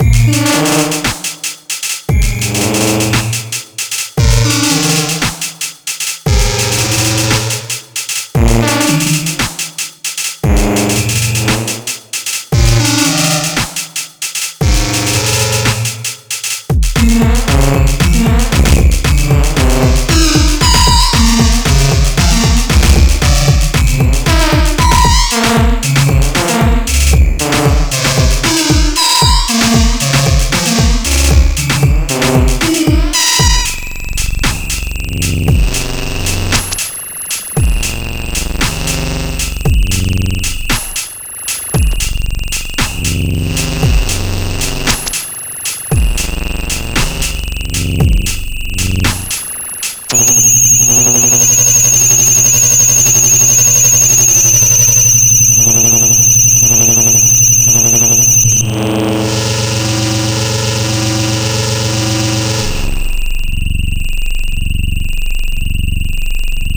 weird, electronica, surreal, experimental,